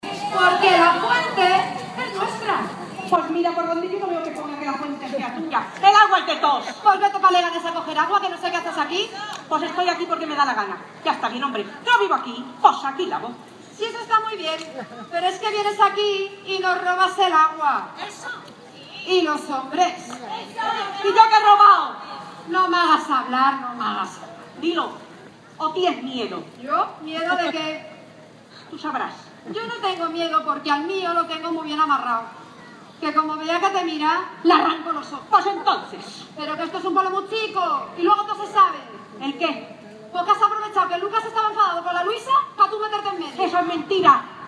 Alcaldía- Fiestas 2 de Mayo: Más de 1.000 vecinos disfrutan a la representación de las escenas costumbristas “Móstoles 1 de Mayo de 1808”
Audio Representación de Escenas Costumbristas